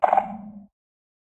sculk_clicking5.ogg